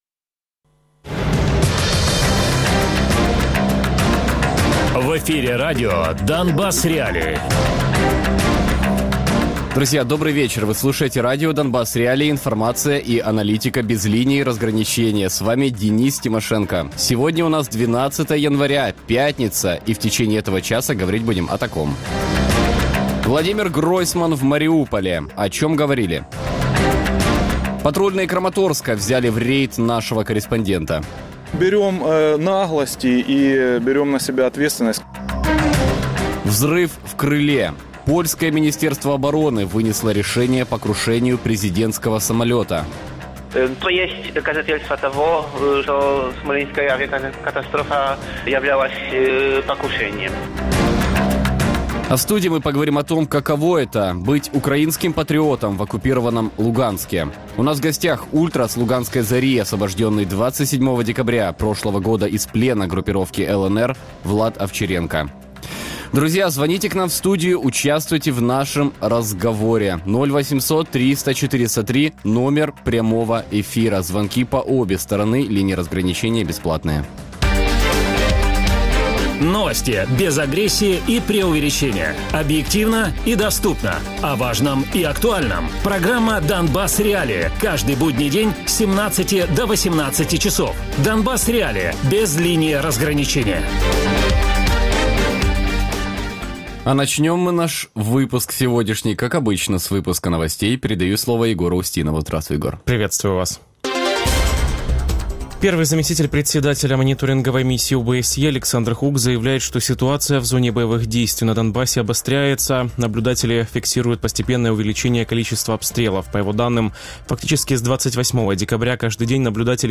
27 грудня звільнений з полону угруповання «ЛНР» Радіопрограма «Донбас.Реалії» - у будні з 17:00 до 18:00.